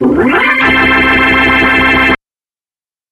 Hammond Organ.mp3